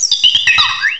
sovereignx/sound/direct_sound_samples/cries/lilligant.aif at 5119ee2d39083b2bf767d521ae257cb84fd43d0e
resample oversampled cries to 13379Hz